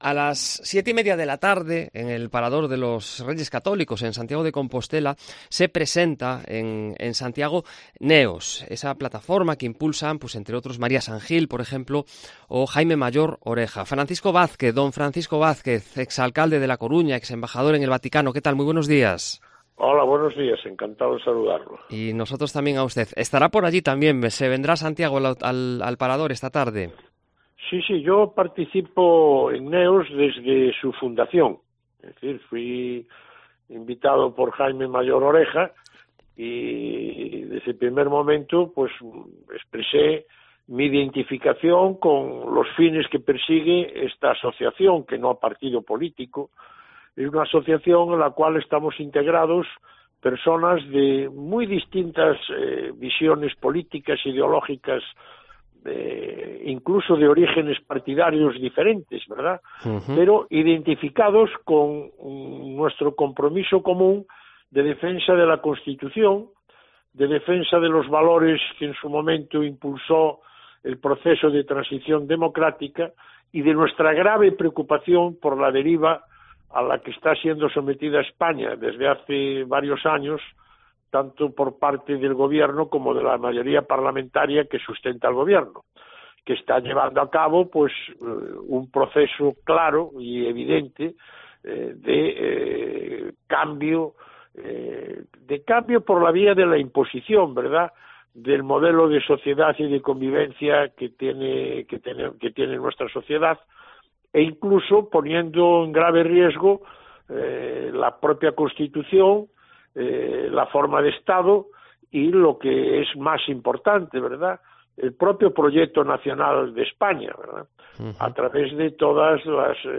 La opinión de Francisco Vázquez: el gobierno va de dislate en dislate De esa ley del sí es sí, o de la eliminación del delito de sedición hemos hablado hoy en Cope Galicia con Francisco Vázquez, ex alcalde de A Coruña y ex embajador en el Vaticano. Lo peor, a su juicio, es que todo obedece a un plan predeterminado para cambiar la sociedad.